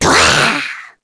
Nia-Vox_Attack4_kr.wav